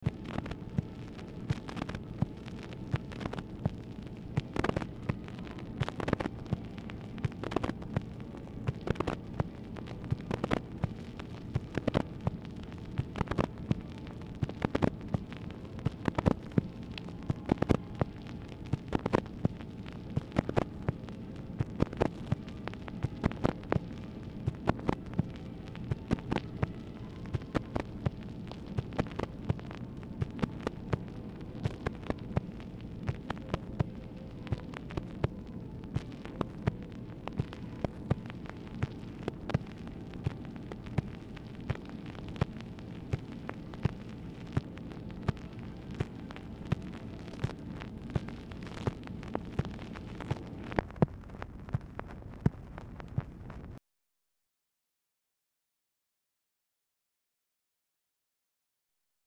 OFFICE NOISE
Oval Office or unknown location
MUSIC AUDIBLE IN BACKGROUND
Telephone conversation
Dictation belt